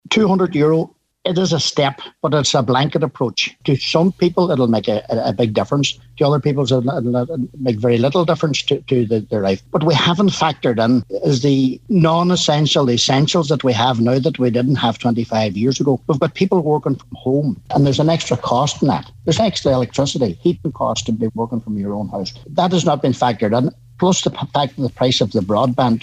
On today’s Nine til Noon Show, the Acting Cathaoirleach of Inishowen Municipal District Cllr Nicholas Crossan said the blanket approach does not recognise the changing circumstances faced by many people………